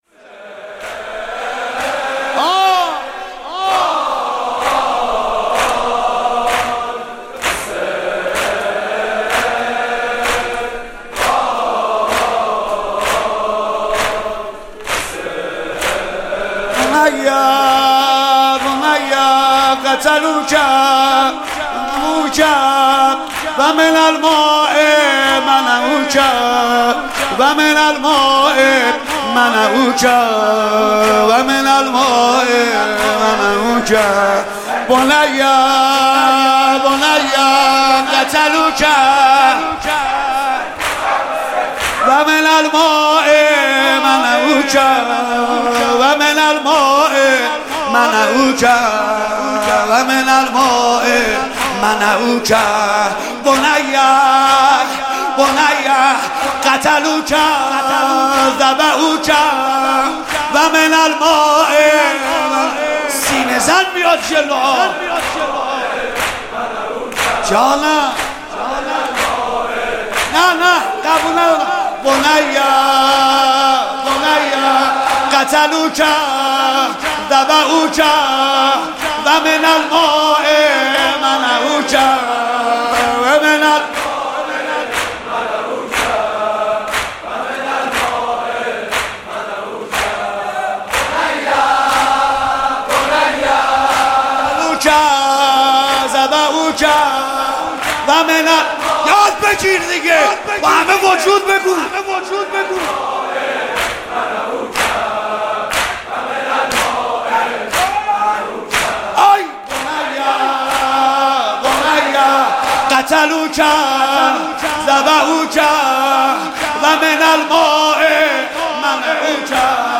«شهادت امام جواد 1395» زمینه: بنی قتلوک ذبحوک